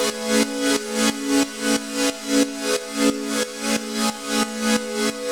Index of /musicradar/sidechained-samples/90bpm
GnS_Pad-MiscA1:8_90-A.wav